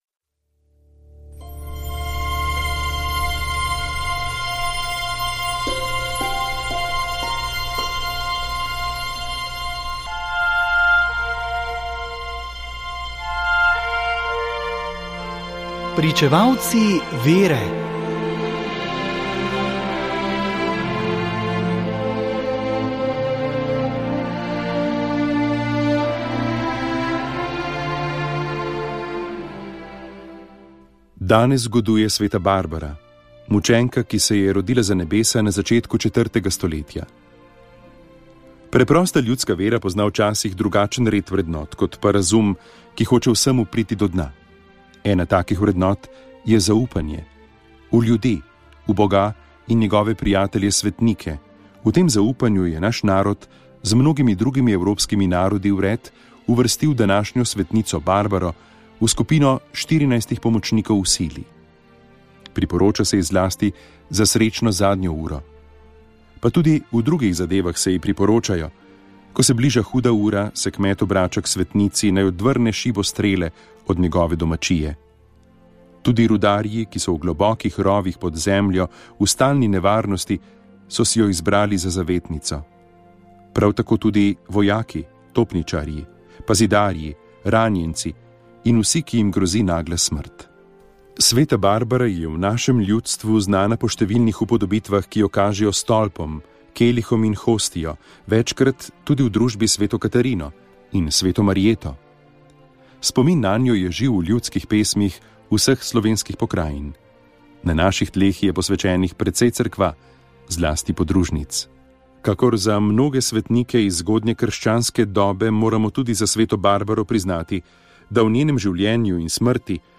Iz knjige Svetnik za vsak dan Silvestra Čuka se vsak dan na Radiu Ognjišče prebira o svetniku dneva.